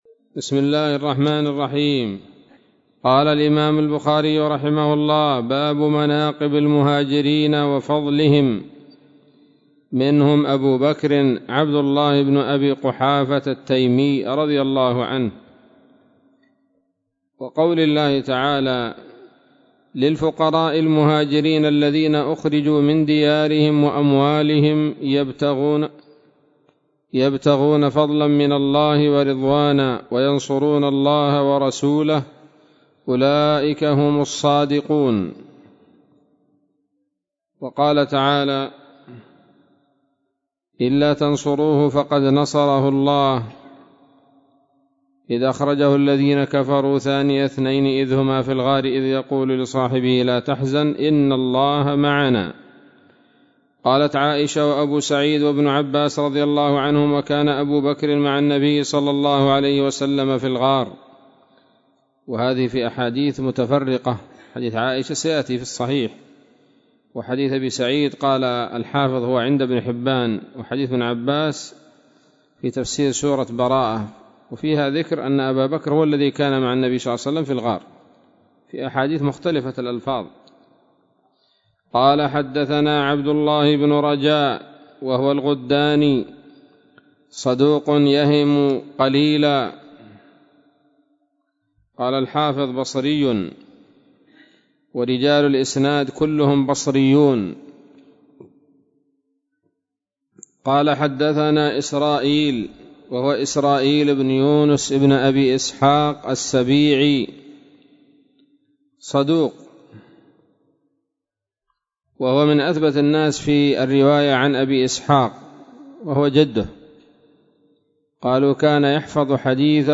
الدرس الثاني من كتاب فضائل أصحاب النبي صلى الله عليه وسلم من صحيح البخاري